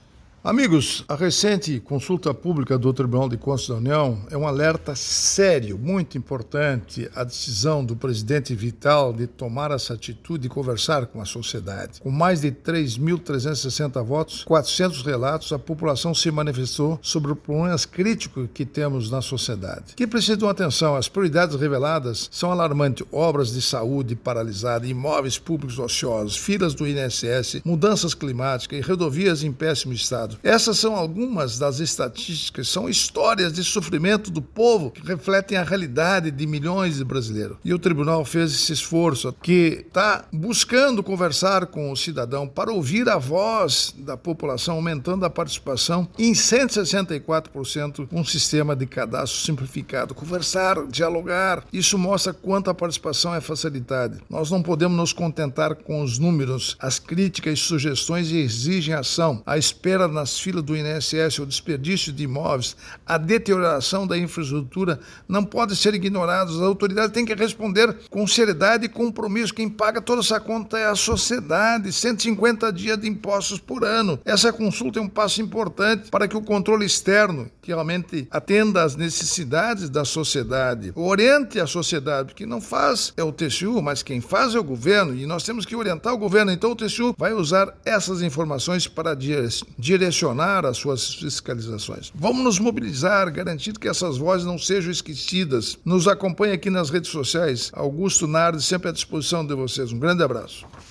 Comentário de Augusto Nardes, ministro do Tribunal de Contas da União.